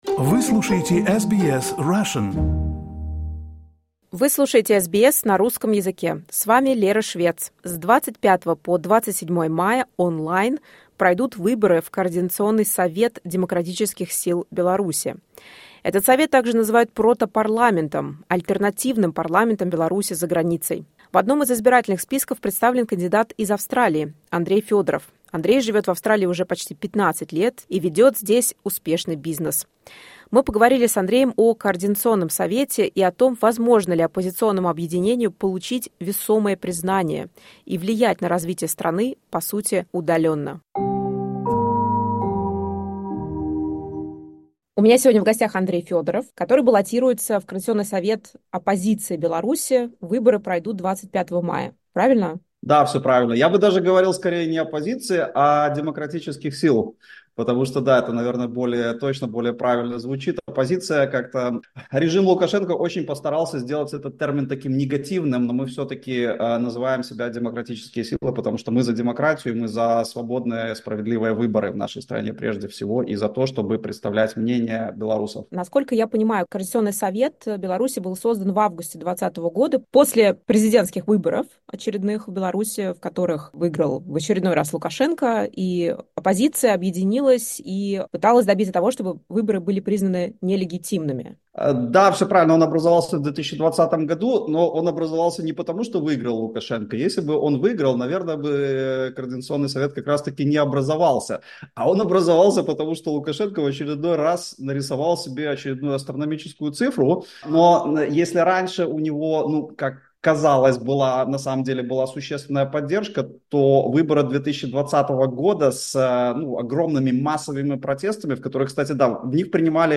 SBS Russian поговорили с ним о совете и о том, действительно ли возможно объединять оппозиционные силы.